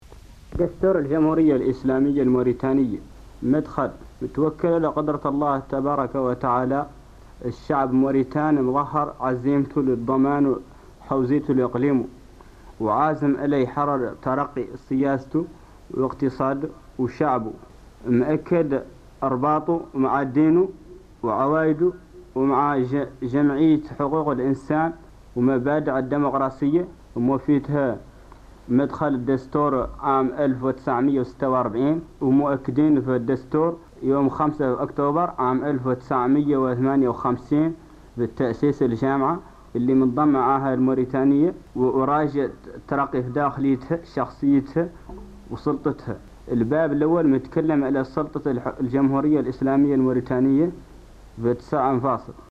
تسجيل إذاعي نادر يشرح ديباجة دستور1961 بالحسانية
افي إطار تغطيتها الخاصة لذكرى الاستقلال تنشر الأخبار التسجيل التالي الذي يعد من نوادر التسجيلات المرتبطة بتاريخ الاستقلال الوطني والذي يحتوي شرحا باللهجة الحسانية لديباجة دستور 1961 المعلن غداة الاستقلال